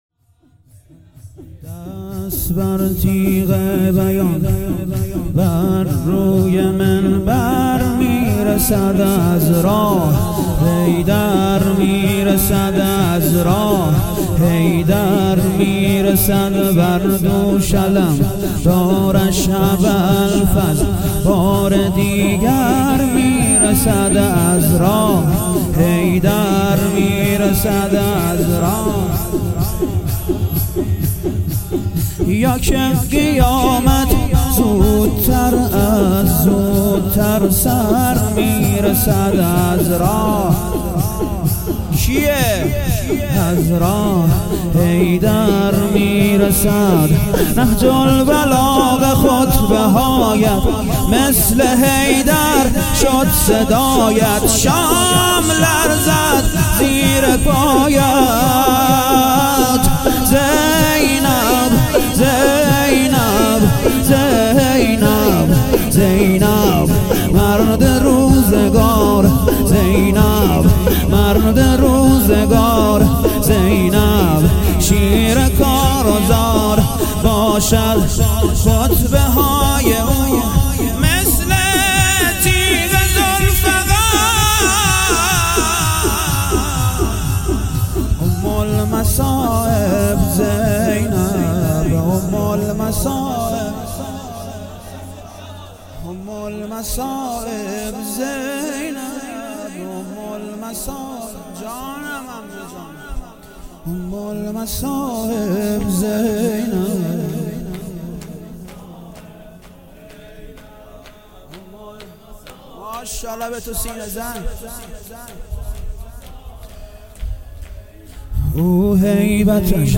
هیئت ام ابیها(س)-اهواز
شور